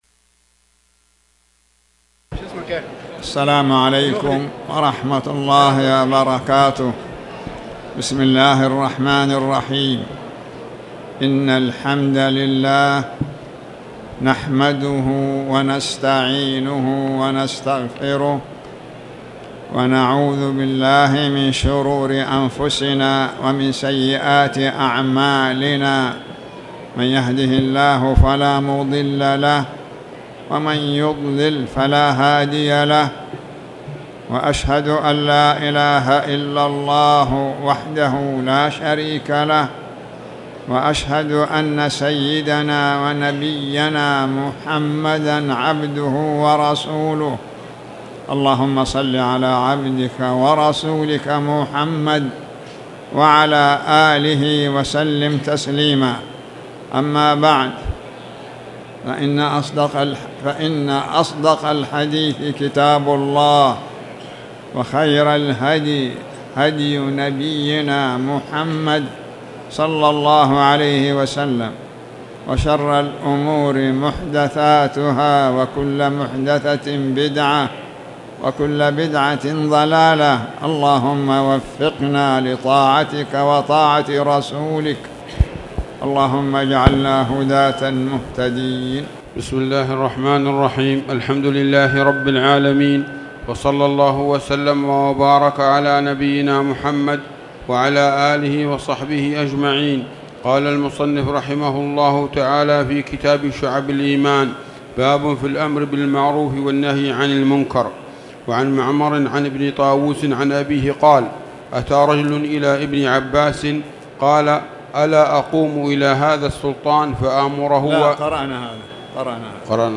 تاريخ النشر ٢١ رجب ١٤٣٨ هـ المكان: المسجد الحرام الشيخ